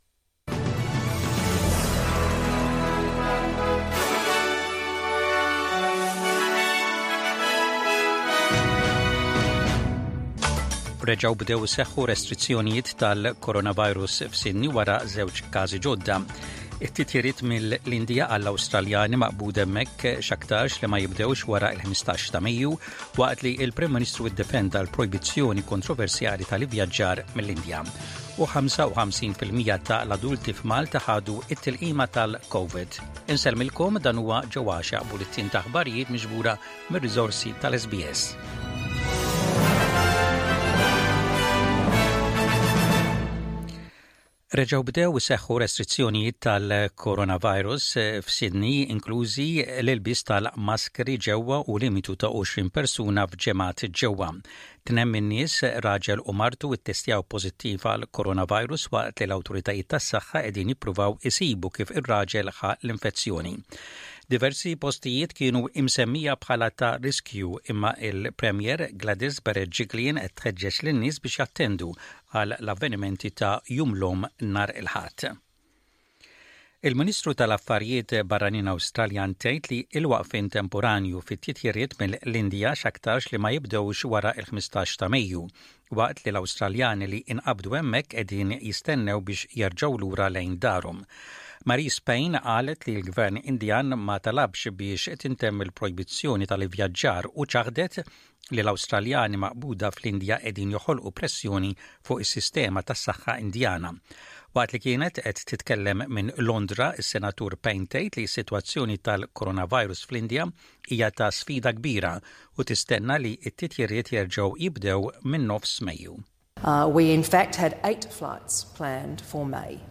SBS Radio | Maltese News: 07/05/21